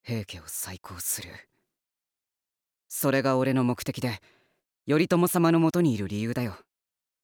平 重衡（CV:市川 蒼）のキャラクター紹介 | イケメン源氏伝 あやかし恋えにし
voice_shigehira.mp3